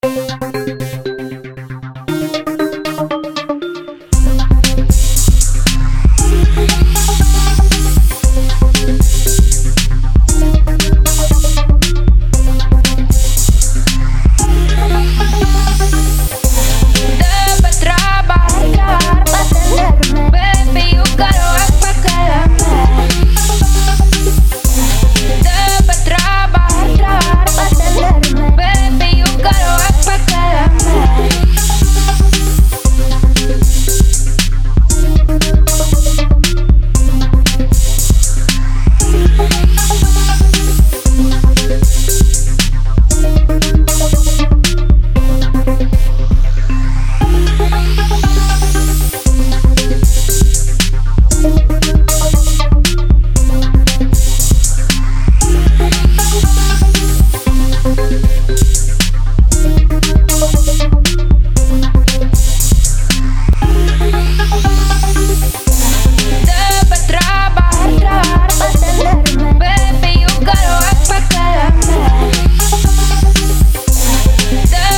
Miami Club Type Beats
Listen to Club Banger  Beat